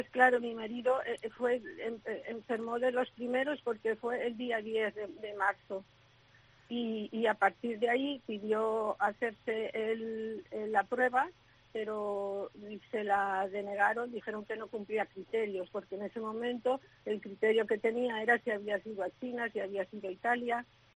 "Fueron 14 días insufribles, esperando cada día la llamda de la UCI, rezando para que mejorara, pero no fue asi, se nos fue, el 7 de abril se fue, todas nuestras ilusiones y nuestra vida", recuerda con la voz quebrada.